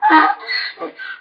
PixelPerfectionCE/assets/minecraft/sounds/mob/horse/donkey/hit1.ogg at c56acfee49e7e1bcd779741dcd49ed8fe864c119